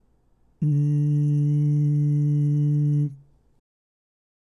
※喉頭は通常位置のチョキの声(ん)